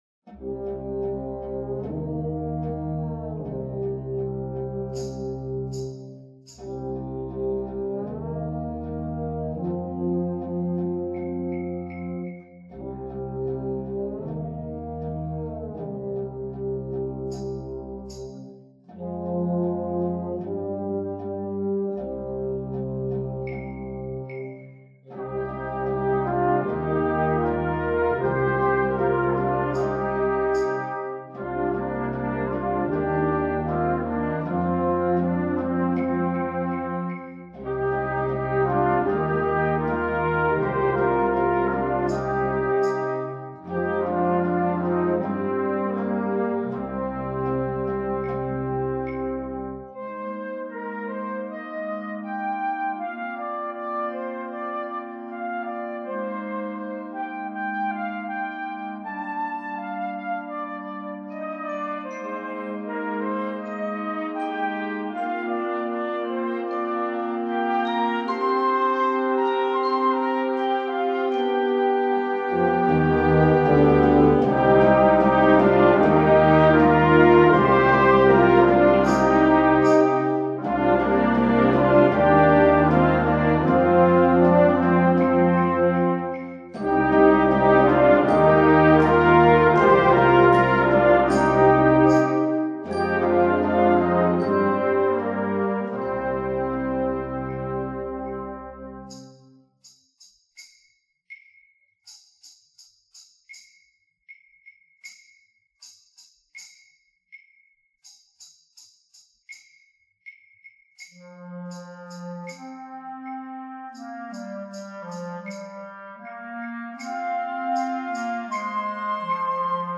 Instrumentation: concert band